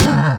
Minecraft Version Minecraft Version snapshot Latest Release | Latest Snapshot snapshot / assets / minecraft / sounds / mob / camel / hurt3.ogg Compare With Compare With Latest Release | Latest Snapshot
hurt3.ogg